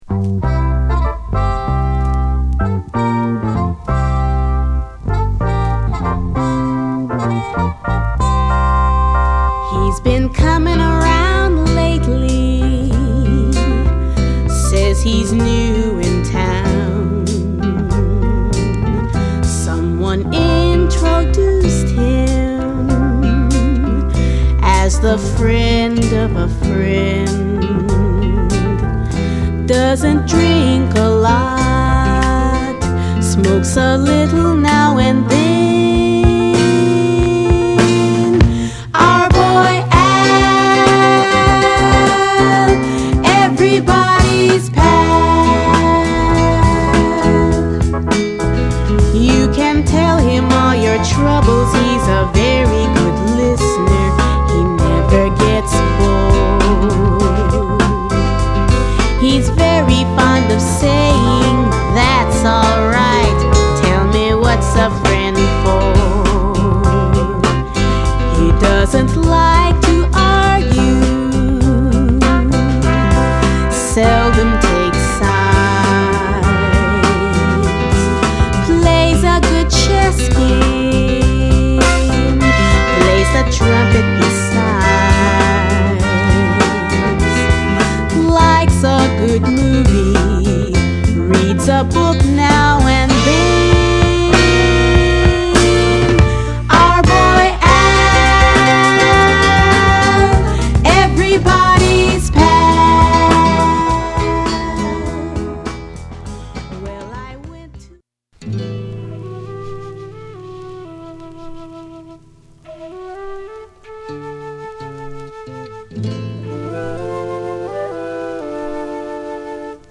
ポップ〜カントリー色の強いアルバムです。